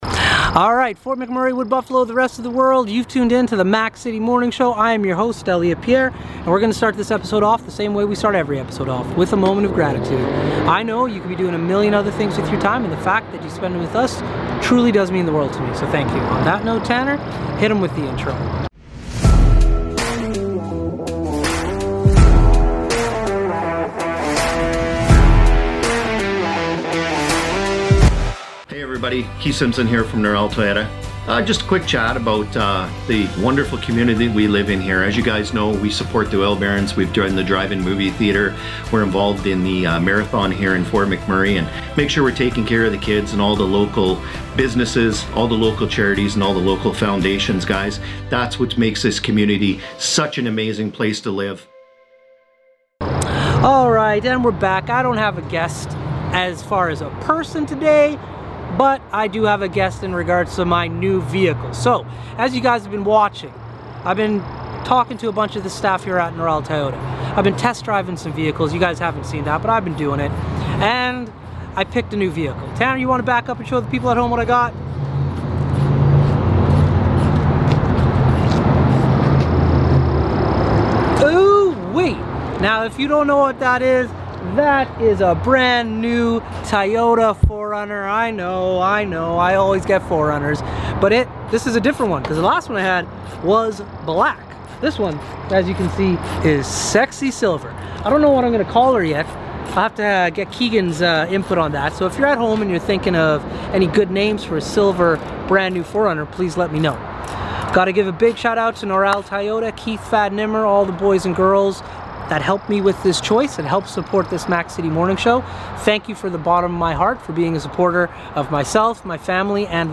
Mac City Morning Show #412: On Location at Noral Toyota